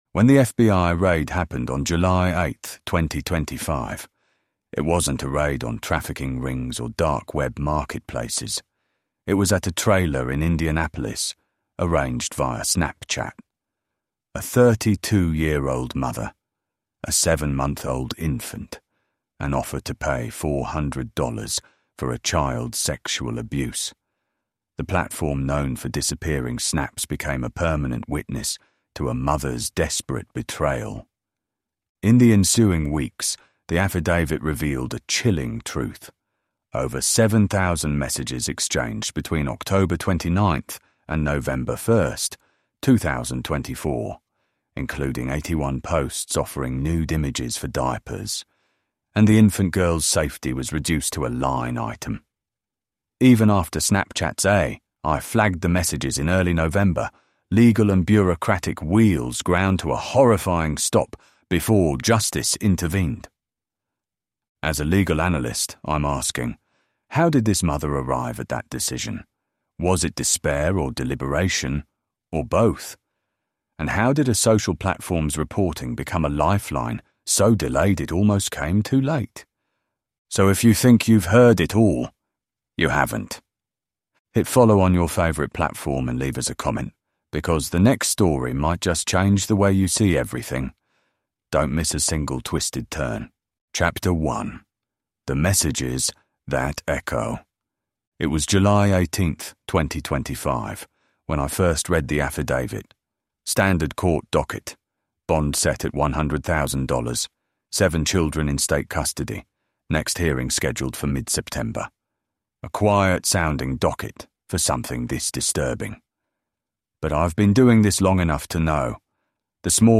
Told from the perspective of a legal analyst, this immersive series investigates the evidence, the courtroom twists, and the child welfare failures that allowed the unimaginable to h